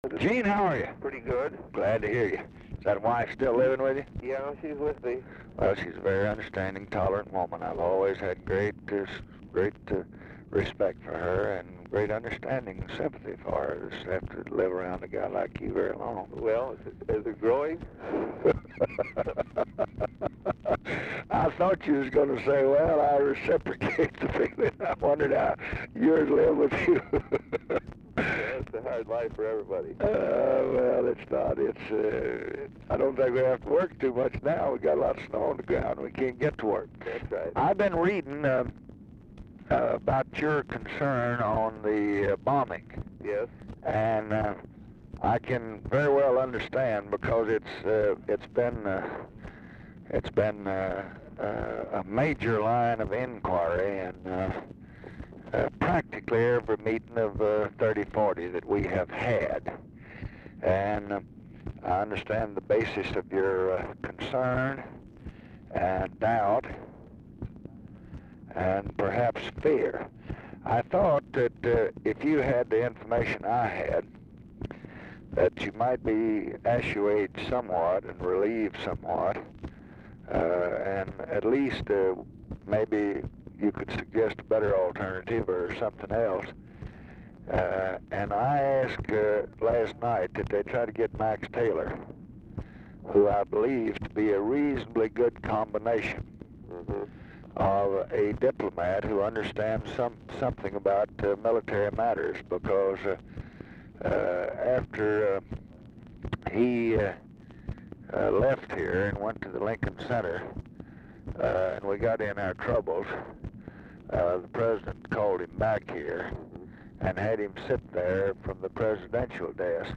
Telephone conversation # 9601, sound recording, LBJ and EUGENE MCCARTHY, 2/1/1966, 9:20AM | Discover LBJ